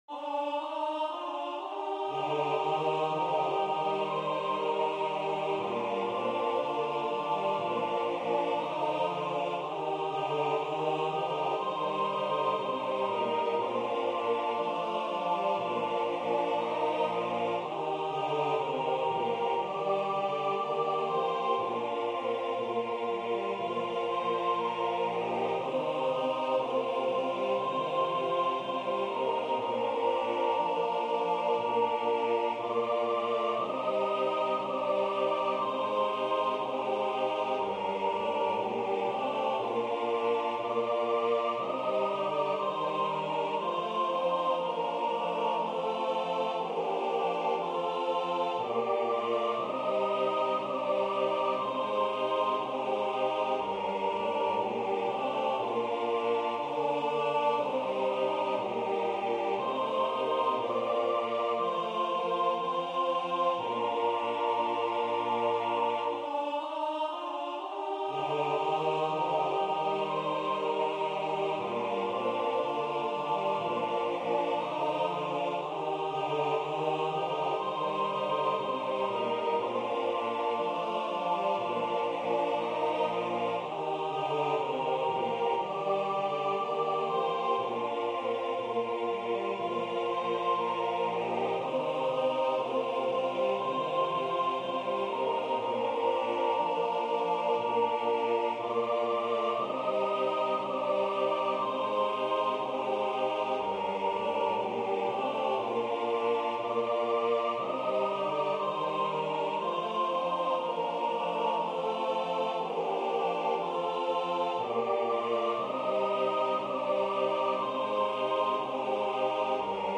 Samuel Longfellow Number of voices: 4vv Voicing: SATB Genre: Sacred, Hymn
Language: English Instruments: A cappella